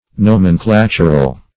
Search Result for " nomenclatural" : The Collaborative International Dictionary of English v.0.48: Nomenclatural \No`men*cla"tur*al\, a. Pertaining or according to a nomenclature.